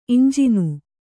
♪ iñjinu